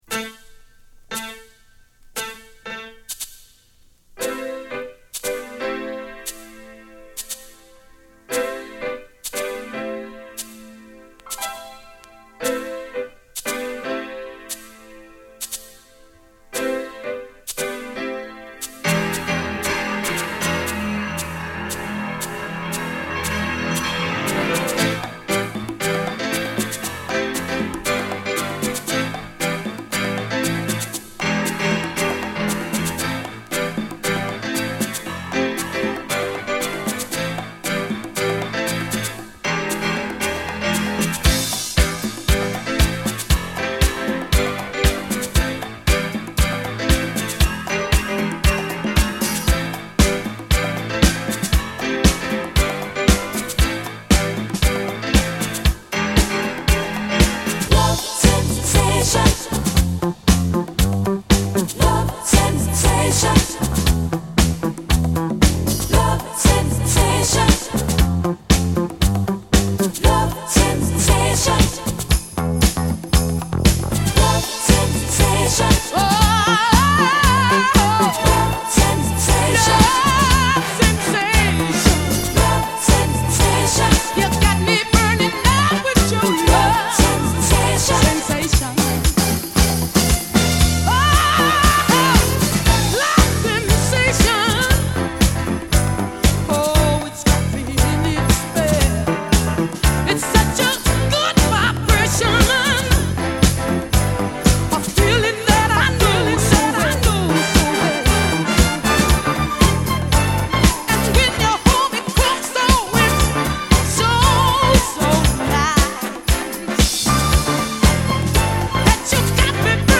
ゴージャスなピアノから、キレの良いビート。